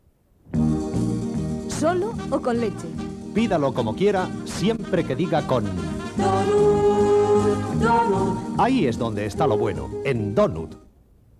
Procedent d'una cinta magnetofònica de bobina oberta de l'estudi Cima S.A. de Madrid